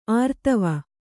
♪ ārtava